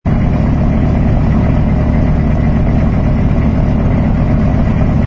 engine_pi_freighter_loop.wav